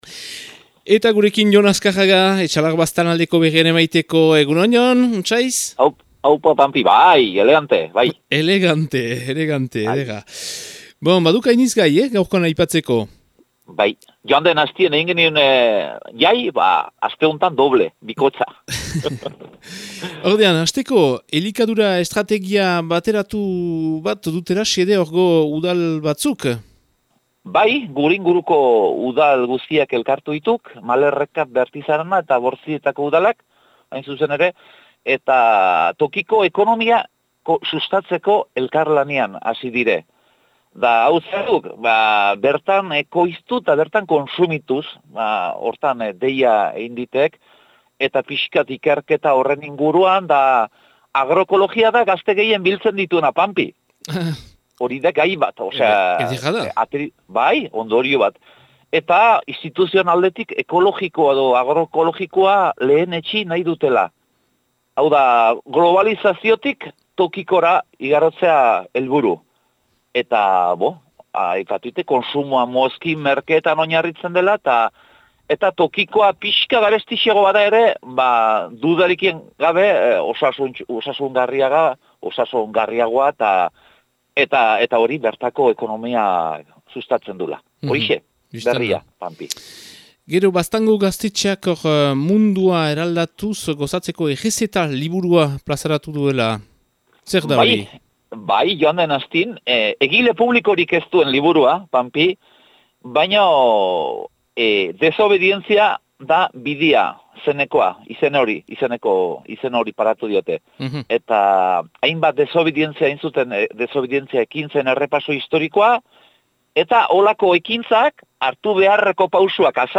Azaroaren 18ko Etxalar eta Baztango berriak